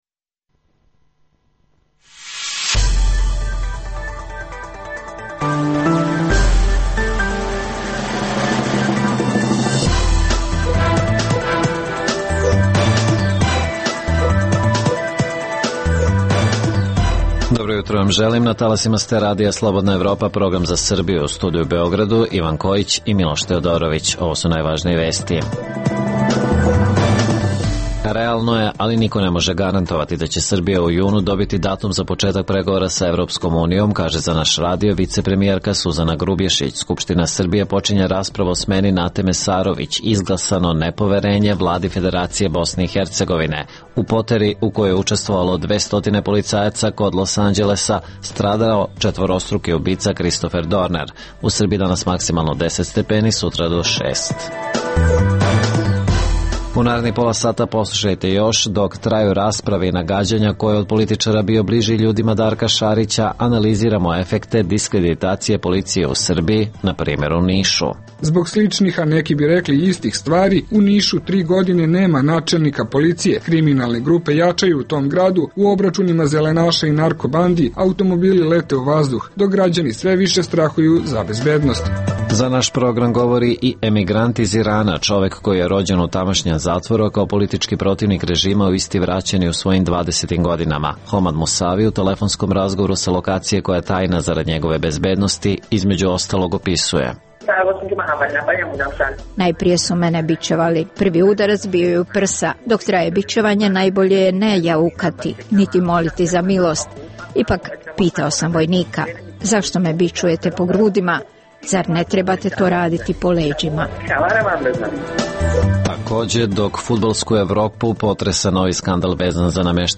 - Realno je, ali niko ne može garantovati, da će Srbija u junu dobiti datum za početak pregovora sa Evropskom unijom, kaže u intervjuu iz Pariza za RSE vicepremijerka Suzana Grubješić.